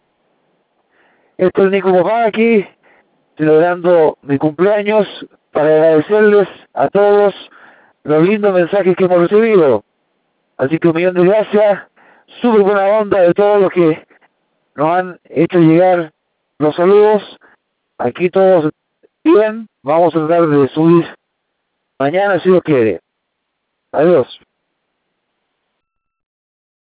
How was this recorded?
Desde las gélidas laderas del Monte Denali a 5200 m de altitud, quiero agradecer todos los saludos y la muy buena onda recibida de tantos amigos y amigas. • Name: Campamento 4